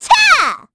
Estelle-Vox_Attack3_kr.wav